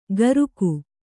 ♪ garuku